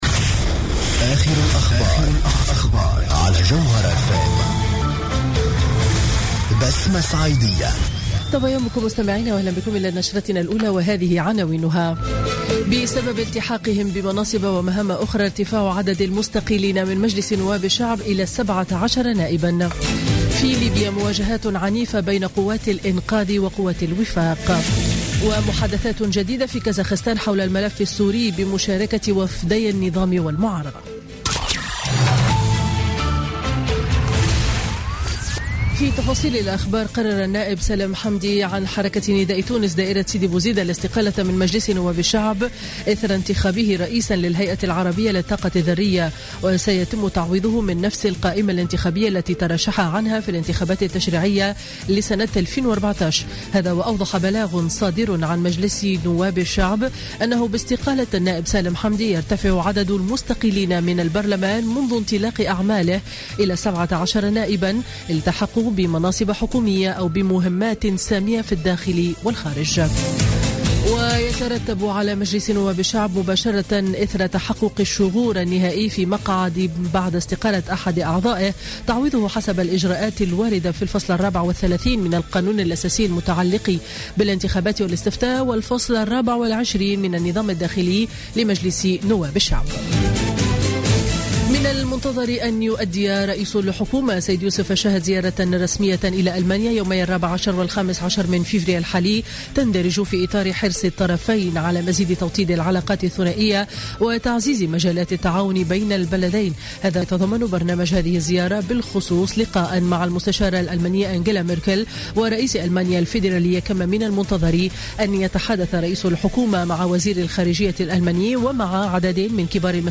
نشرة أخبار السابعة صباحا ليوم الأحد 12 فيفري 2017